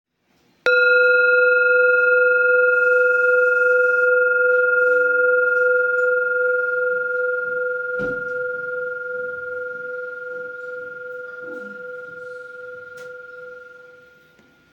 Jambati Singing Bowl Singing Bowl, Buddhist Hand Beaten, Antique Finishing, Jhumkabati
Material Seven Bronze Metal
It can discharge an exceptionally low dependable tone.